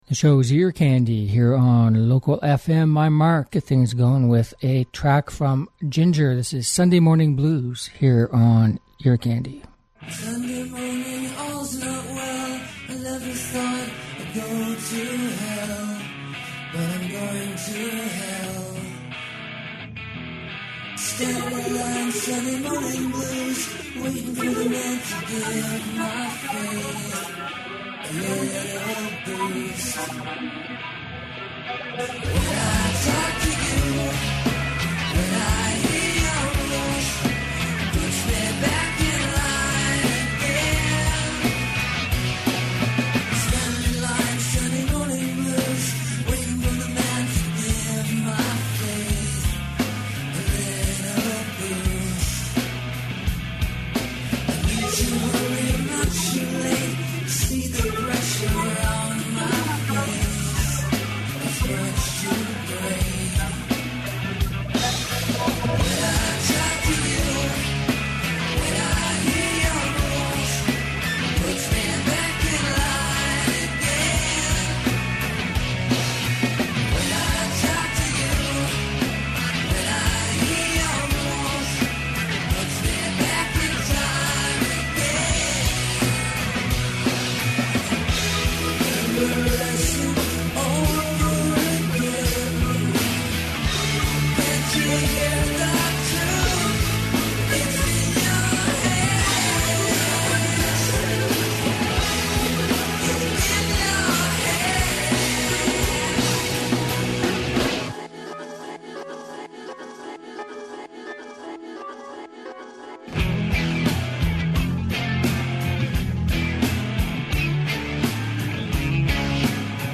Catchy Uptempo Music From Old and New Artists